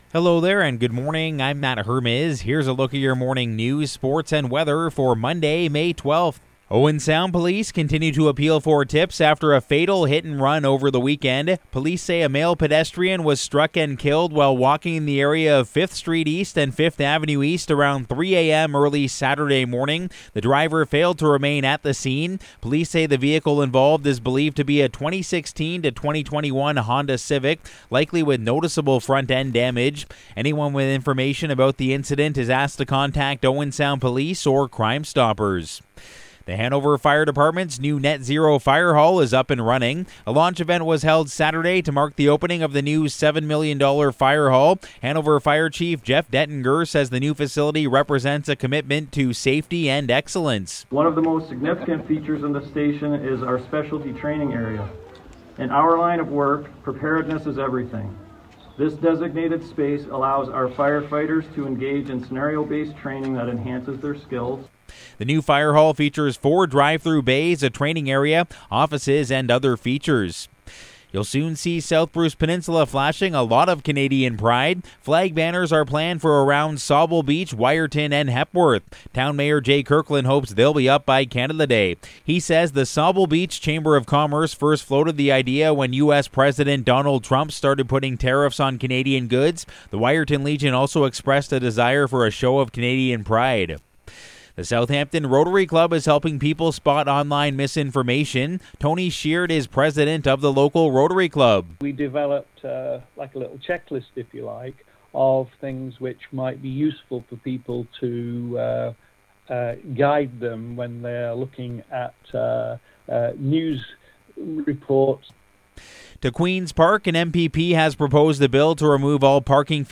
Morning News – Monday, May 12